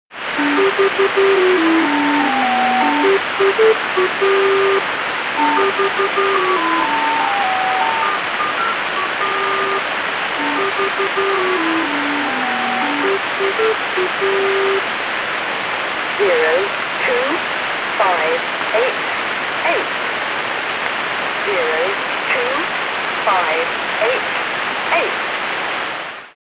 Any short wave radio enthusiast will eventually bump into stations which continuously transmit spooky stuff like this.